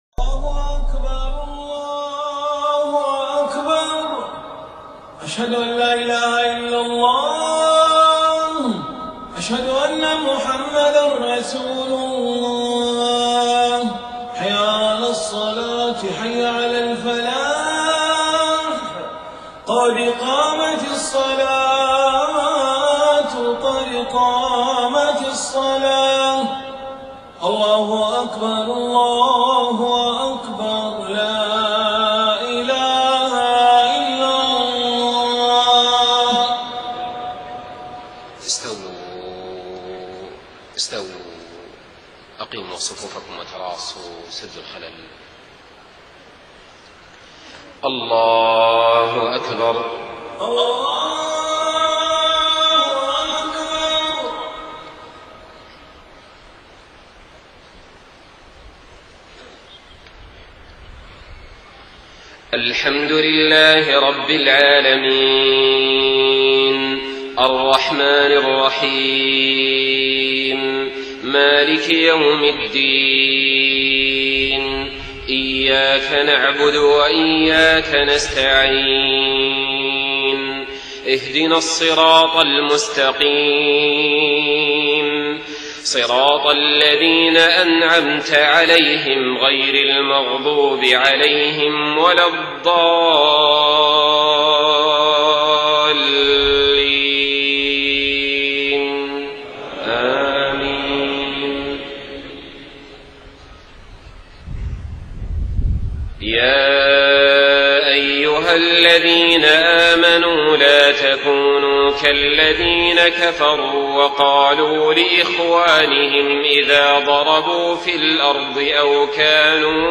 صلاة العشاء 4 محرم 1430هـ من سورة آل عمران 156-165 > 1430 🕋 > الفروض - تلاوات الحرمين